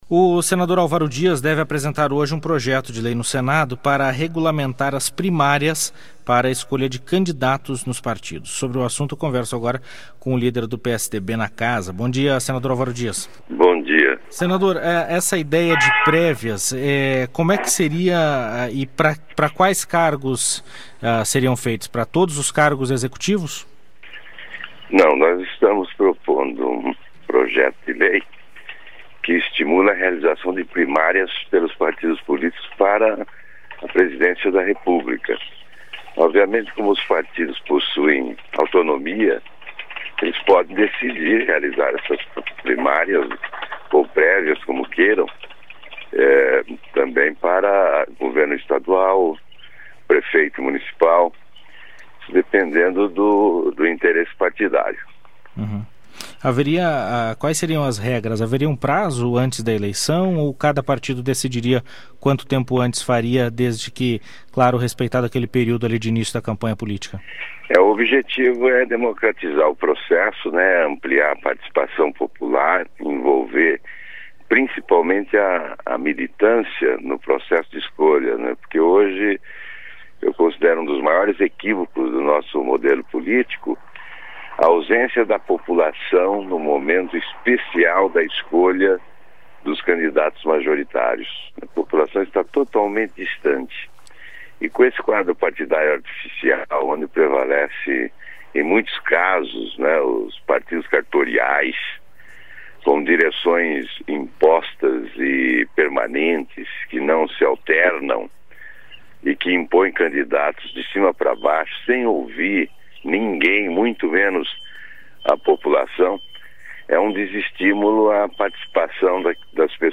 Entrevista com o senador Alvaro Dias (PSDB-PR).